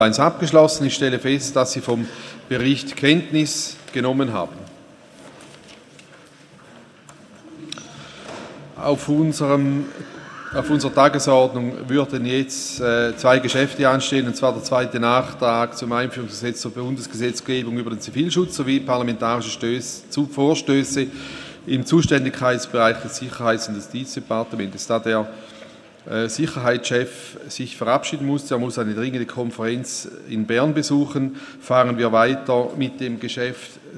30.11.2015Wortmeldung
Session des Kantonsrates vom 30. November bis 2. Dezember 2015